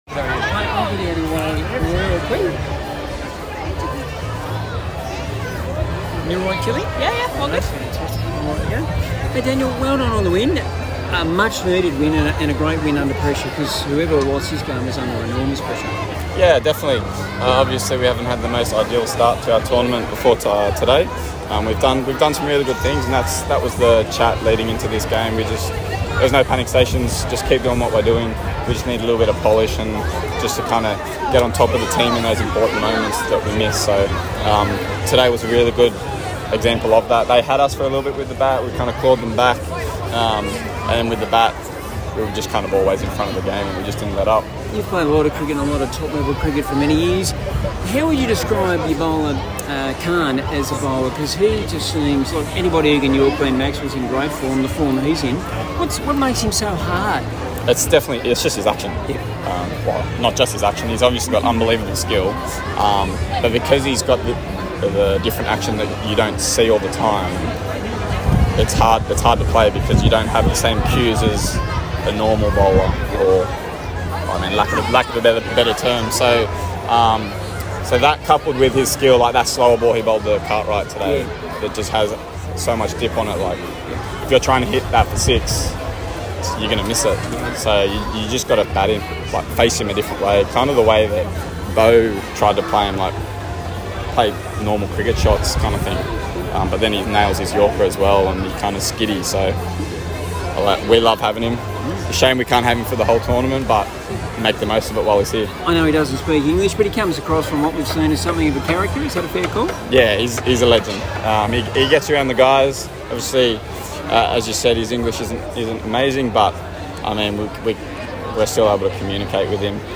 Daniel Sams (4 for 33) Post-Match Interview: Sydney Thunder's 5-Wicket Win vs. Melbourne Stars